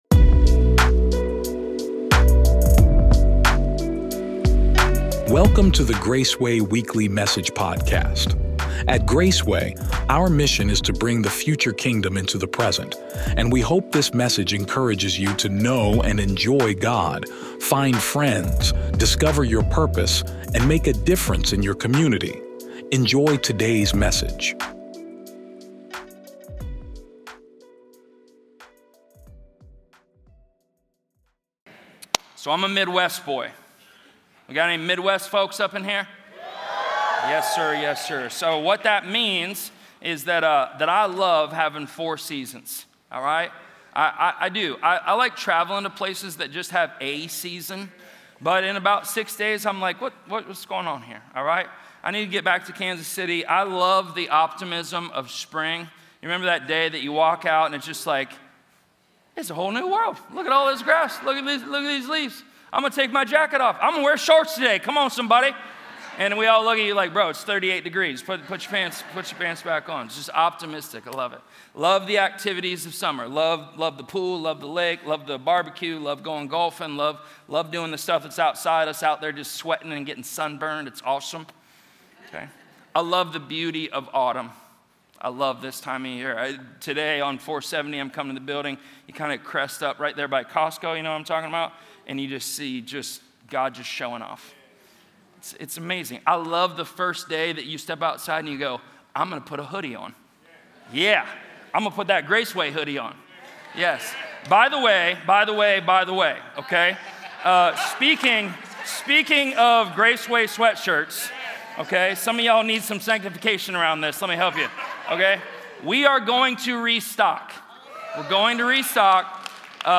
Drawing from Ecclesiastes 3 and Isaiah 53, this sermon explores the truth that there is a time to heal—physically, emotionally, morally, and spiritually.